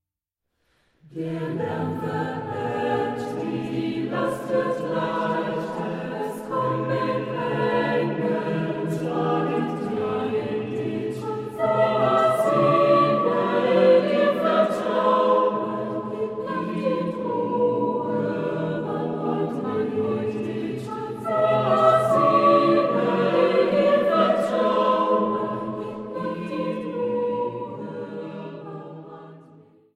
Truhenorgel